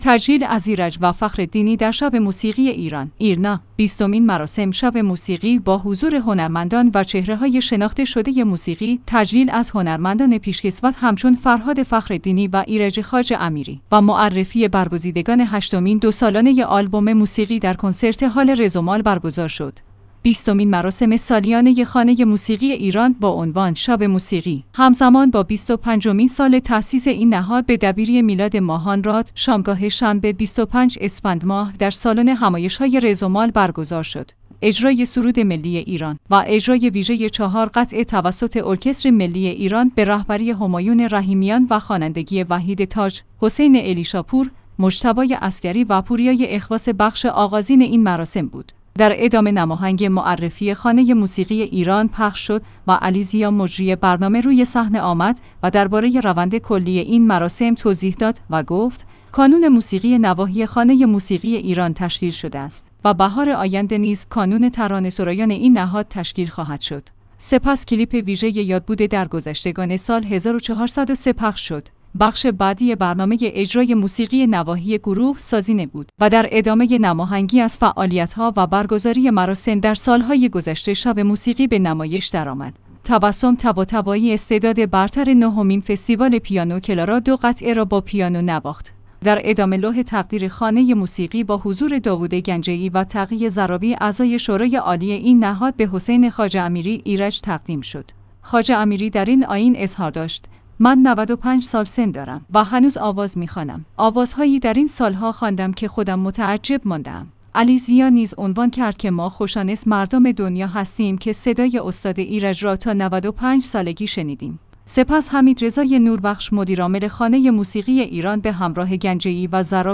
تجلیل از ایرج و فخرالدینی در شب موسیقی ایران
ایرنا/ بیستمین مراسم «شب موسیقی» با حضور هنرمندان و چهره‌های شناخته شده موسیقی، تجلیل از هنرمندان پیشکسوت همچون فرهاد فخرالدینی و ایرج خواجه امیری و معرفی برگزیدگان هشتمین دوسالانه آلبوم موسیقی در کنسرت هال رزمال برگزار شد.